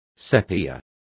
Complete with pronunciation of the translation of sepias.